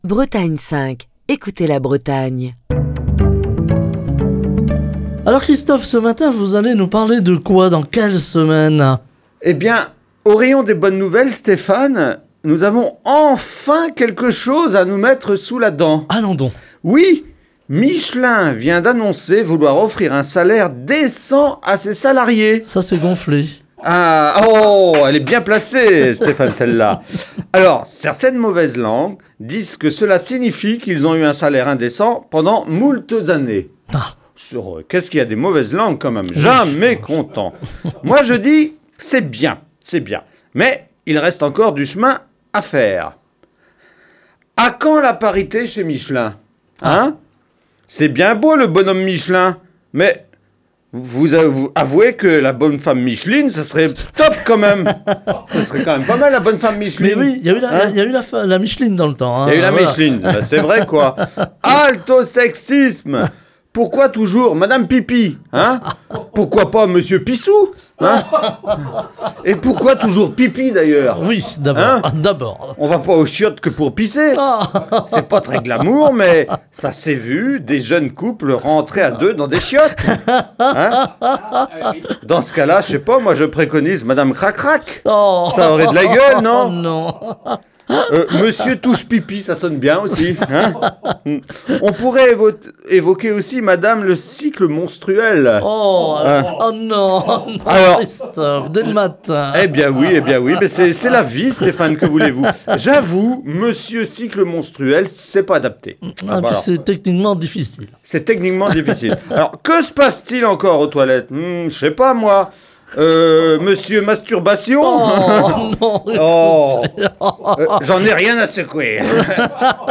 Chronique du 26 avril 2024.